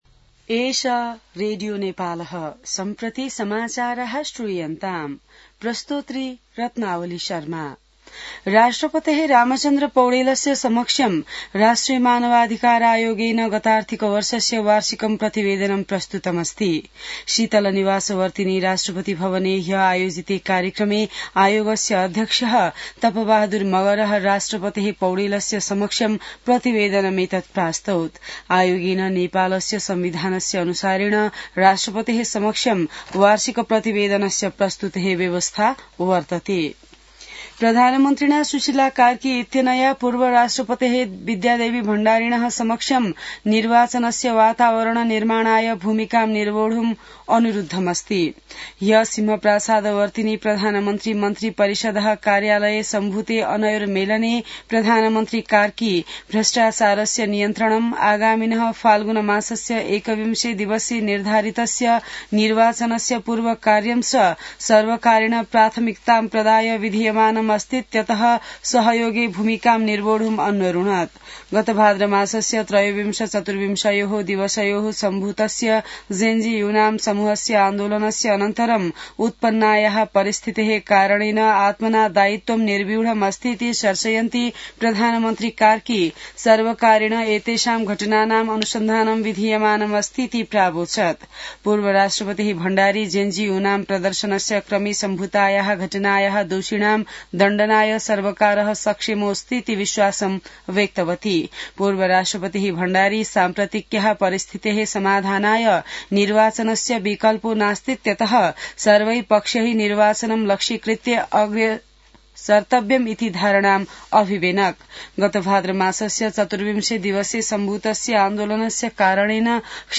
संस्कृत समाचार : २२ मंसिर , २०८२